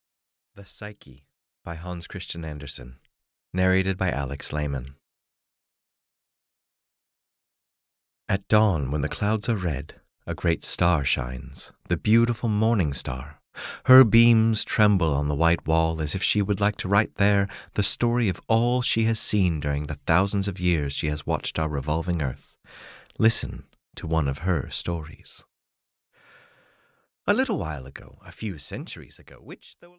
The Psyche (EN) audiokniha
Ukázka z knihy